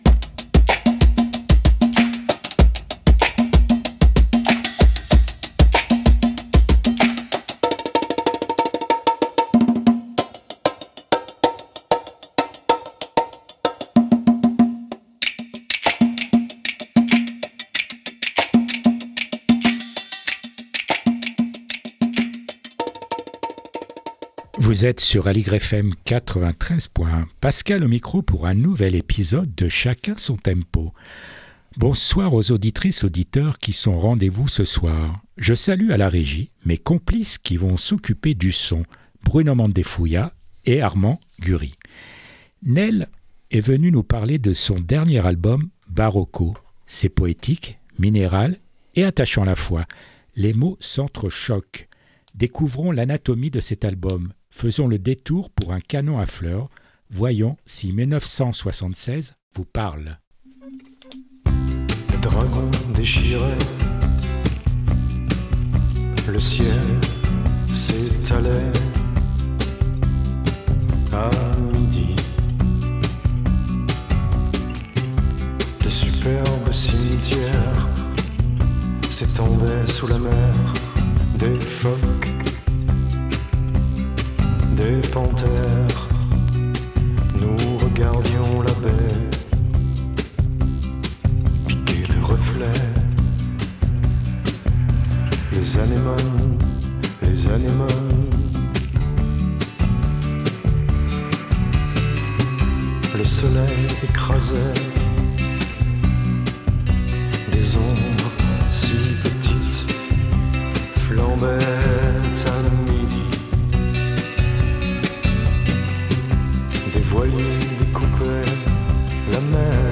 Portrait radiophonique
guitare/voix